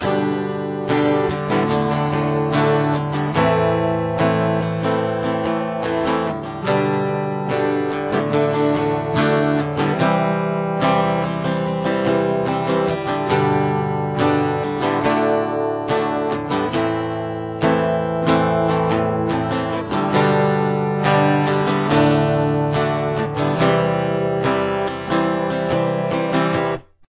Here is what both parts sound like together :